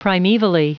Prononciation du mot primevally en anglais (fichier audio)
Prononciation du mot : primevally